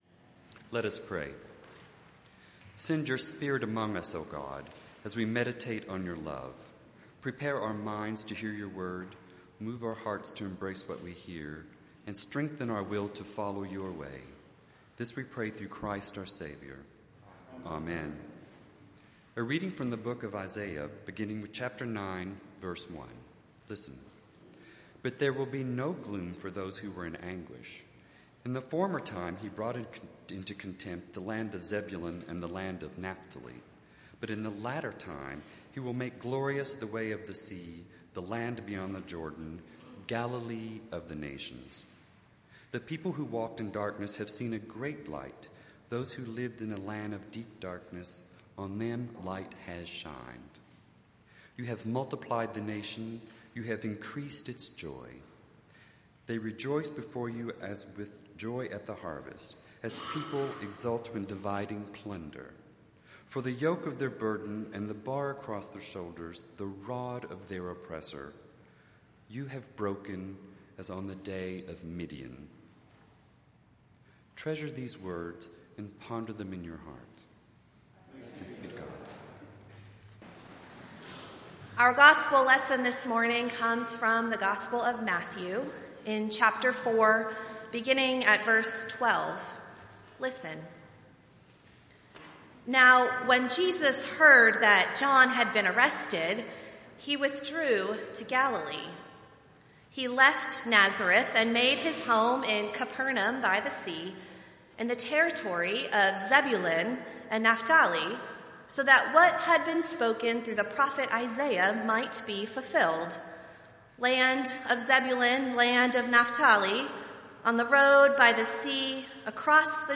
A sermon preached during a worship service at First Presbyterian Church (Lexington, KY) on April 27, 2025 (the second Sunday of Easter).
The scripture reading begins at 13:26; the sermon begins at 16:13.